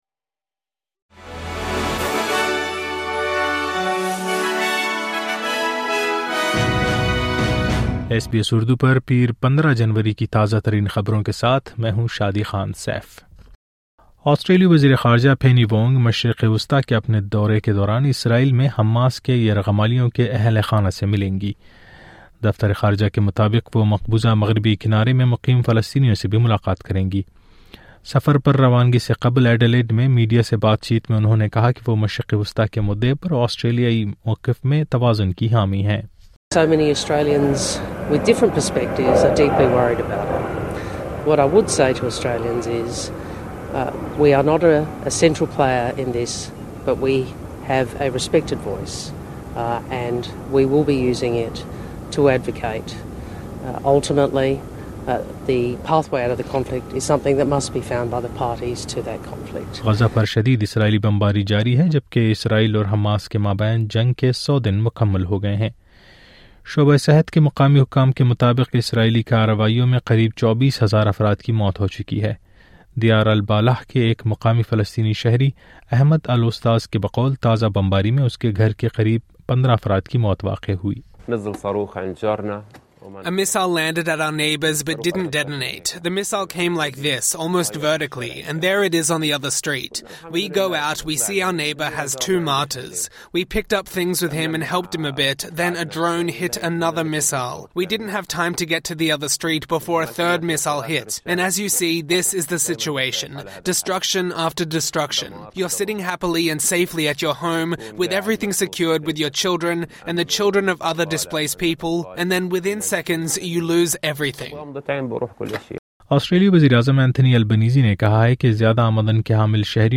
نیوز فلیش: پیر15جنوری آسٹریلوی وزیر خارجہ مشرق وسطیٰ روانہ، غزہ جنگ کے سو دن اور آسٹریلین اوپن کا دوسرا روز